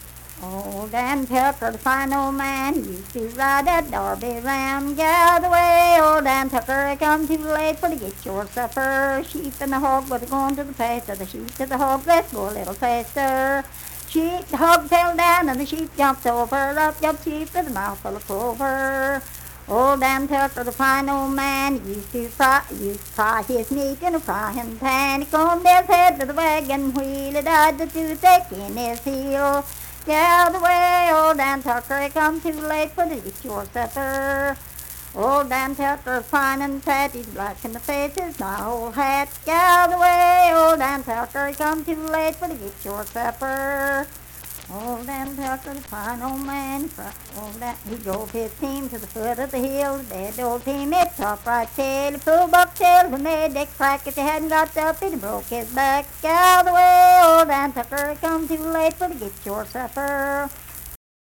Unaccompanied vocal music performance
Verse-refrain 5(4) & R(2).
Dance, Game, and Party Songs
Voice (sung)